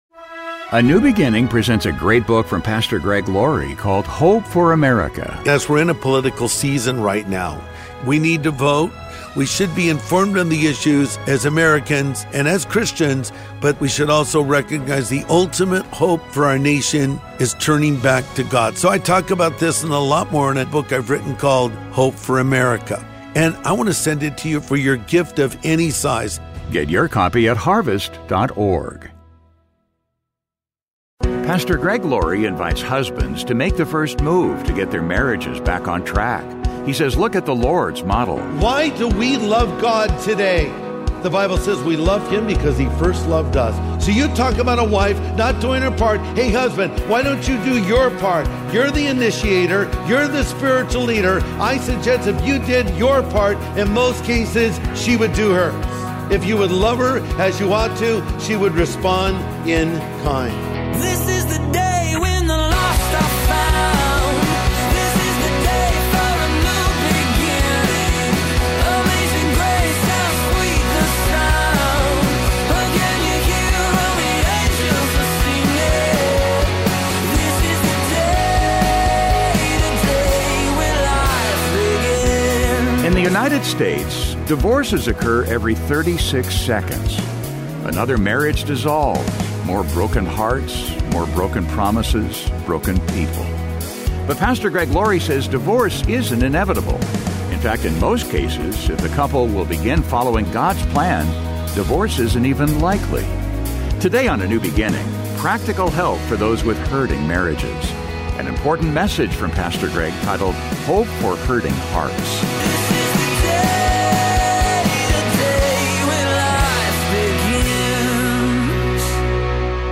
Today on A NEW BEGINNING, practical help for those with hurting marriages. An important message from Pastor Greg titled "Hope for Hurting Hearts."